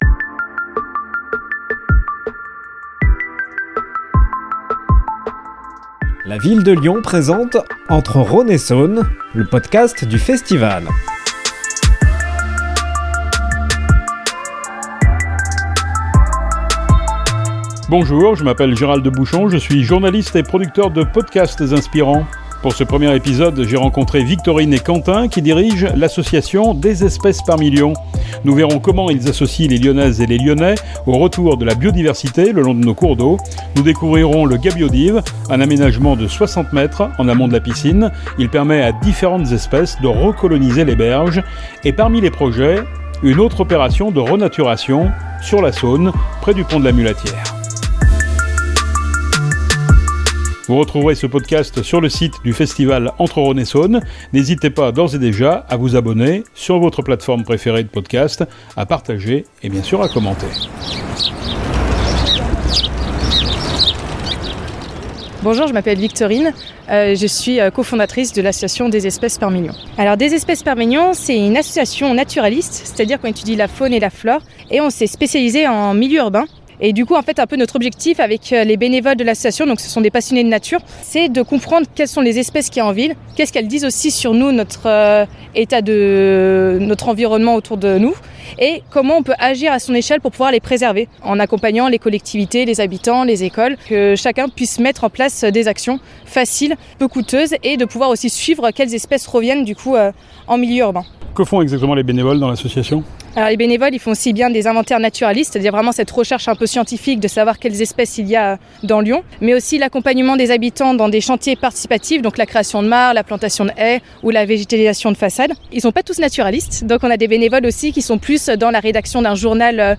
Gabiodiv : pour le retour de la biodiversité en ville Pour cet épisode, nous nous sommes rendus au bord du Rhône, près du pont de la Guillotière, pour découvrir le GabioDiv.